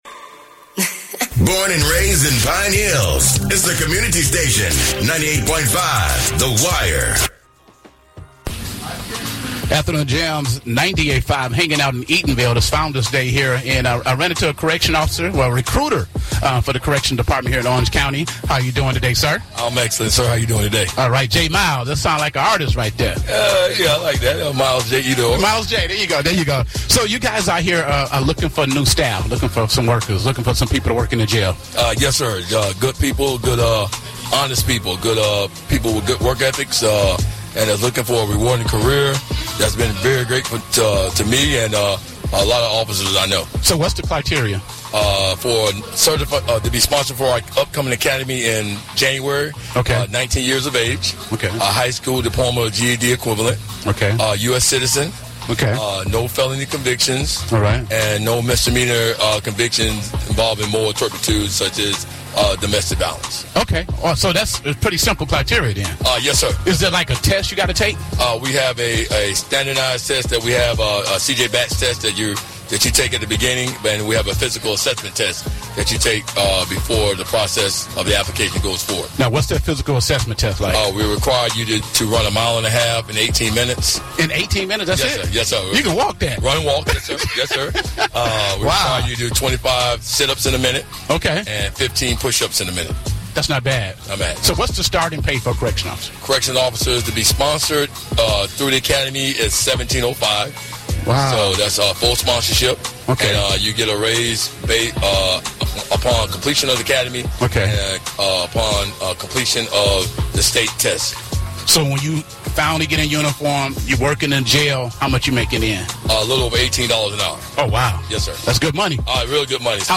While attending Founders Day in Eatonville, Florida.